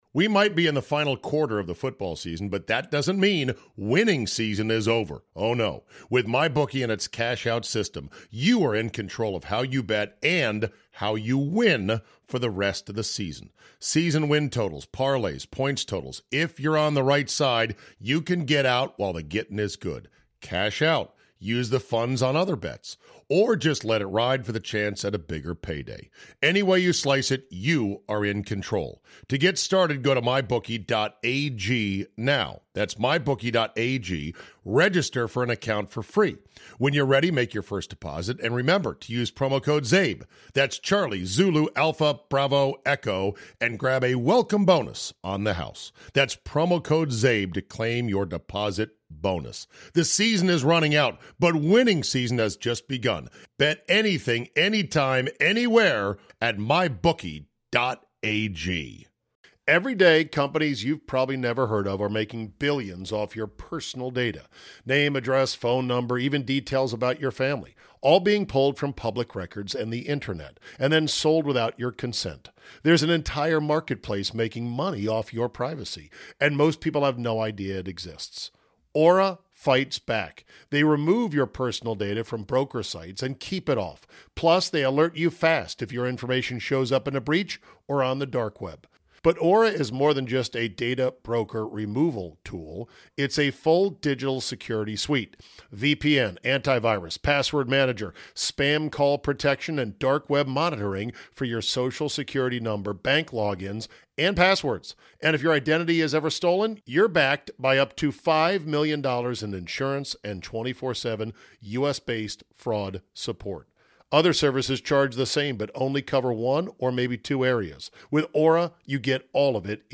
CZABE flies SOLO today, and talks NFL Playoffs, getting 'cocked by the league, expensive and cold games, Jameis Winston is too stupid to employ anymore, and when did the NBA become a league of babies, not men?